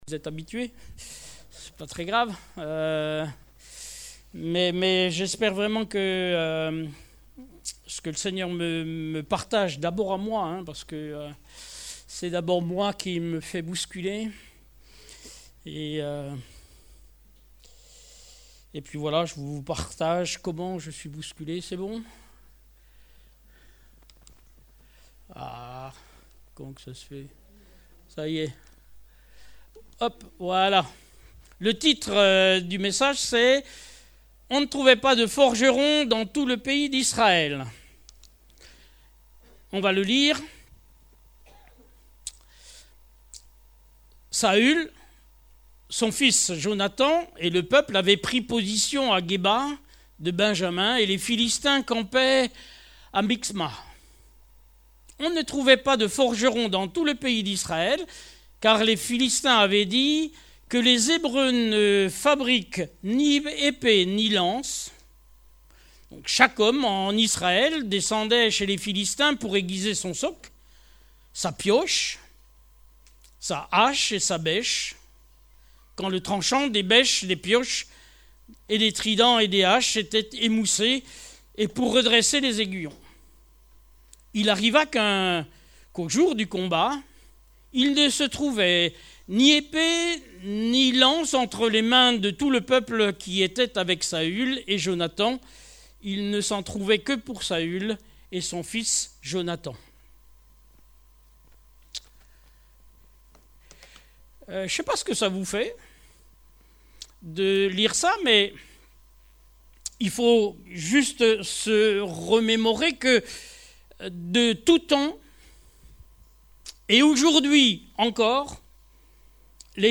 Type De Service: Culte